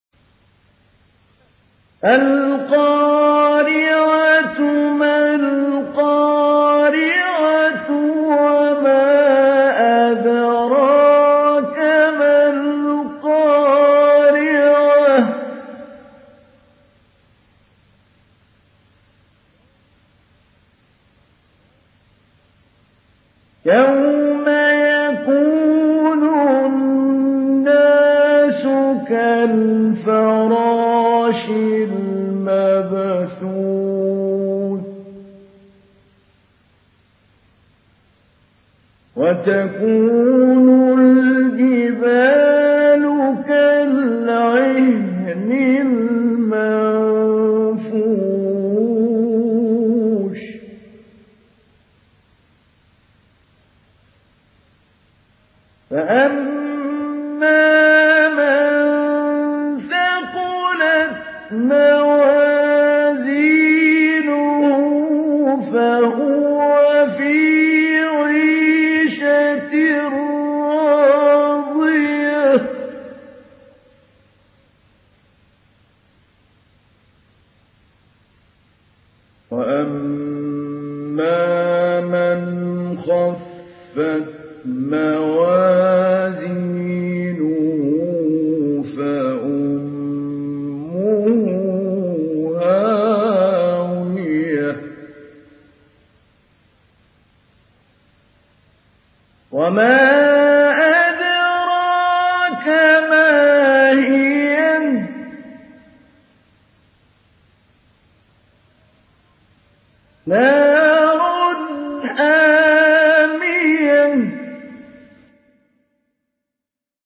Surah Al-Qariah MP3 in the Voice of Mahmoud Ali Albanna Mujawwad in Hafs Narration
Surah Al-Qariah MP3 by Mahmoud Ali Albanna Mujawwad in Hafs An Asim narration.